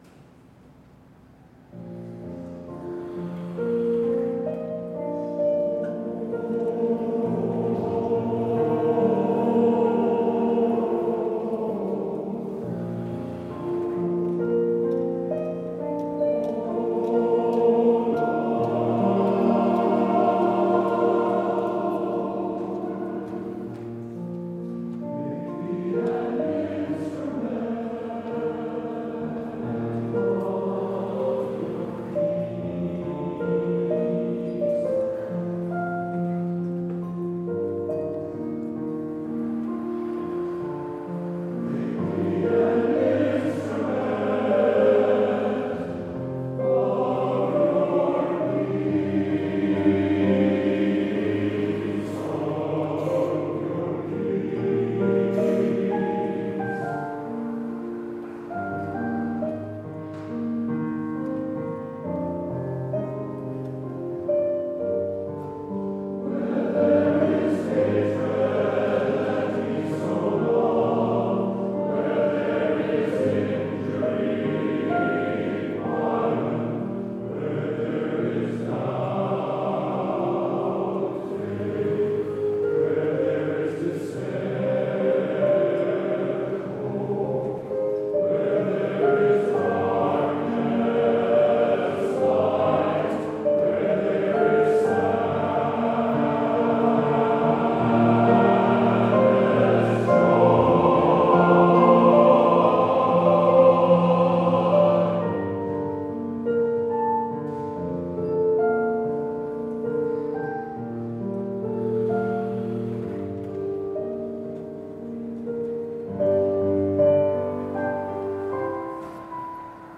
Summer Harmony at Make Music Perkiomenville